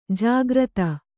♪ jāgrata